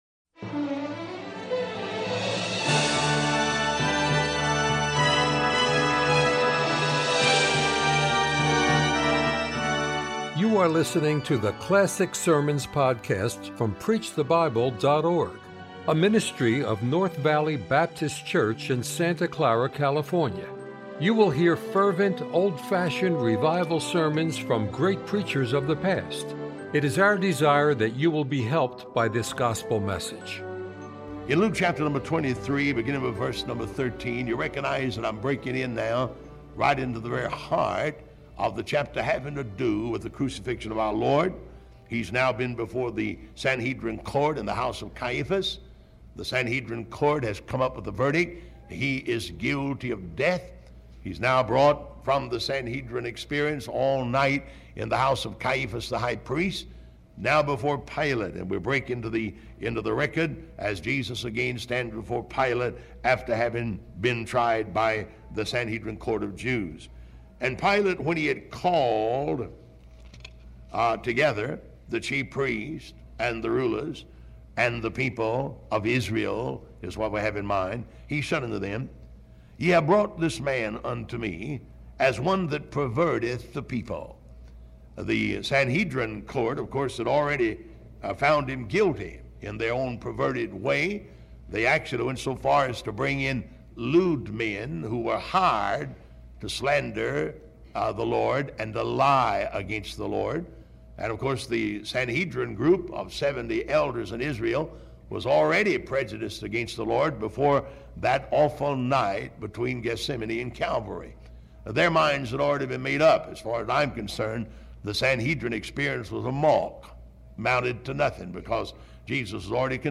We hope you enjoy this classic sermon from a great preacher of the past.